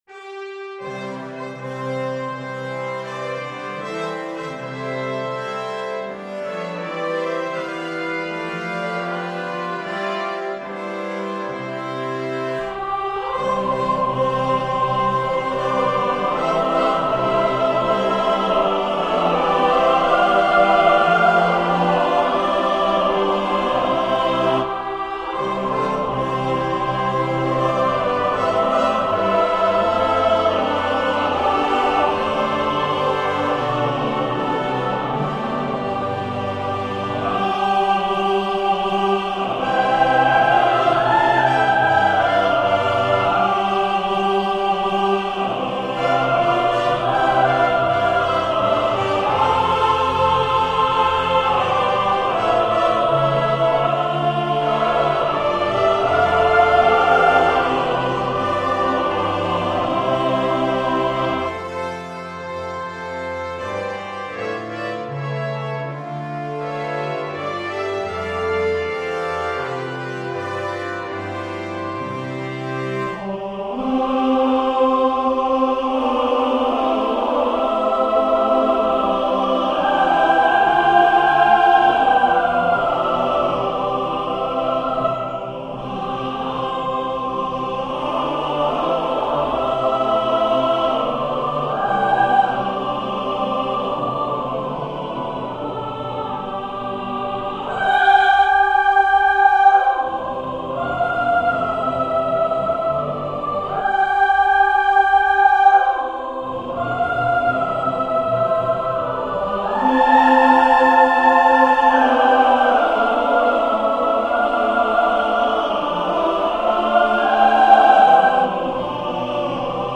Christmas Mash-up No.3 - Choral, Vocal - Young Composers Music Forum
Also, this time the mash-up is accompanied by a brass octet instead of strings.